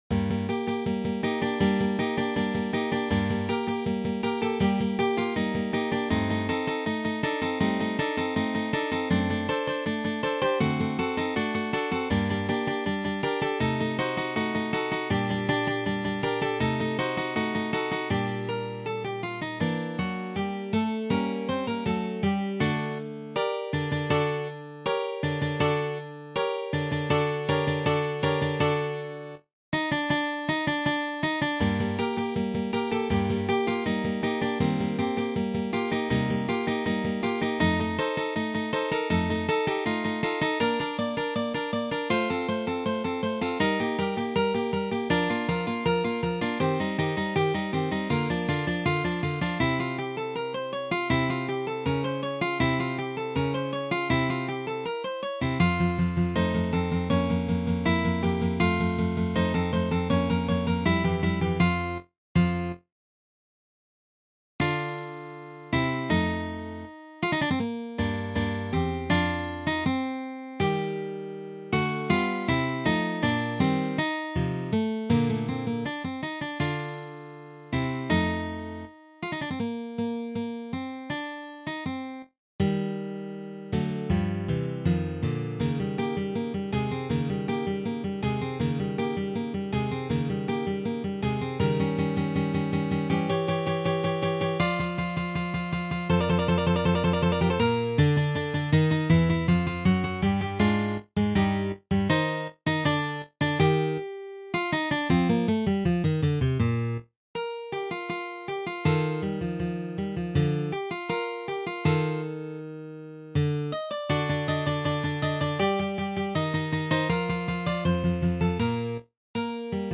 Molto Allegro